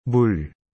A pronúncia é fácil para quem fala português: pense no “m” suave seguido de um “ul” curto, como em “muro”.
A pronúncia é bem direta: algo como “mul”, parecido com o som de “muro” em português.